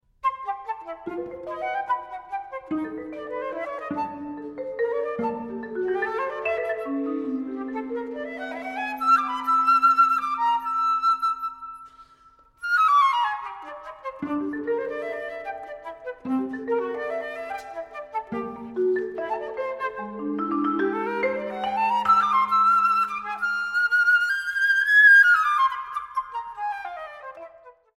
flute
cello
piano